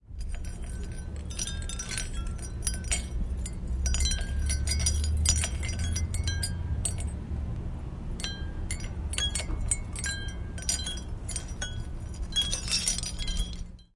风铃
Tag: 风风铃 风铃